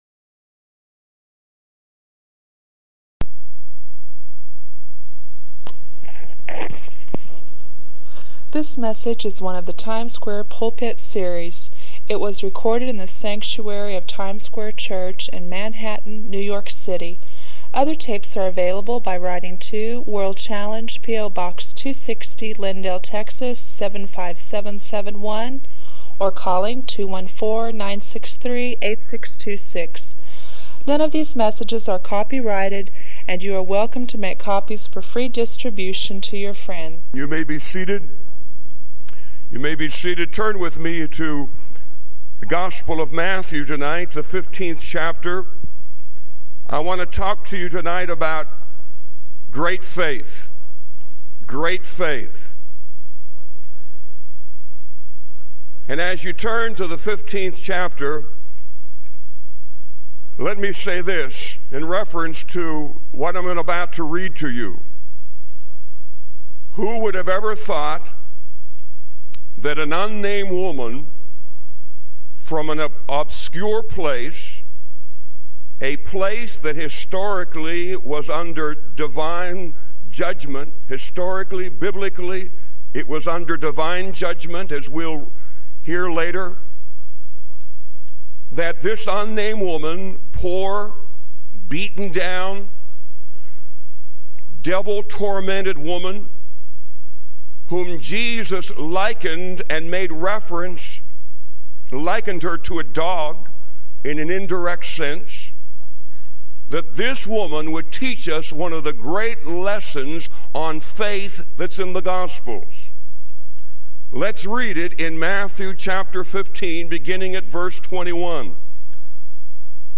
In this expository sermon